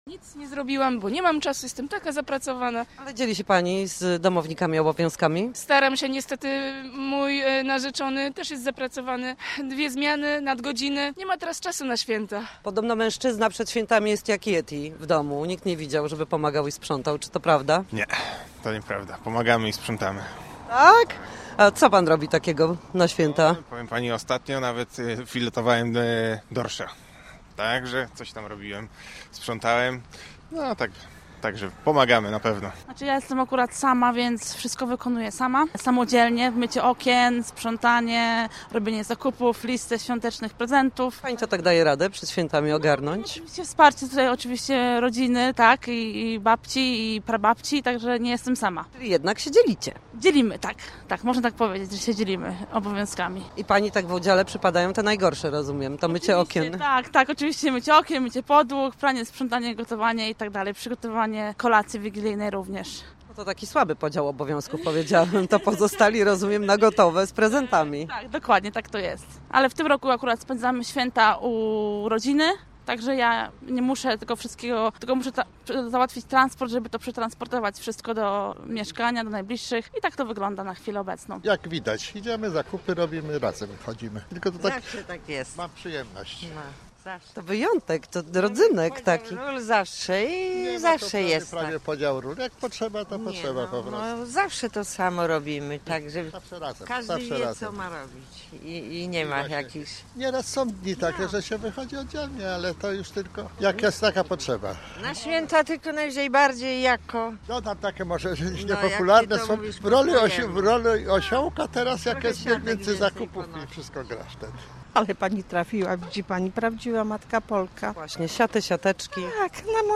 Sprawdziliśmy, jak jest w słupskich domach.